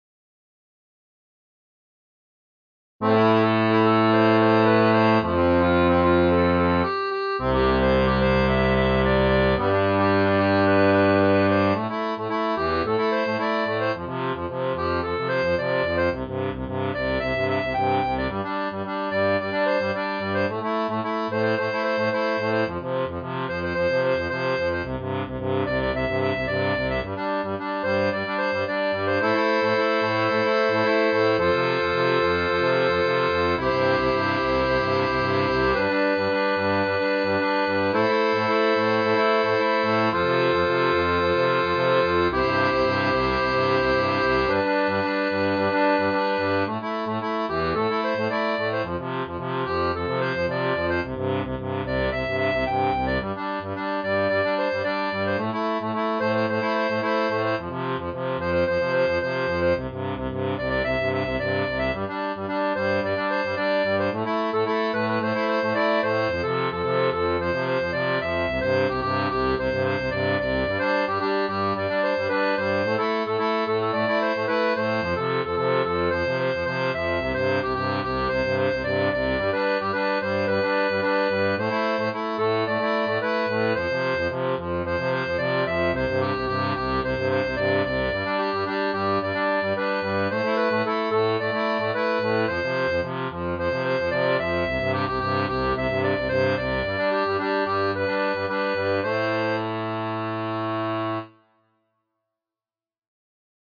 Musique cubaine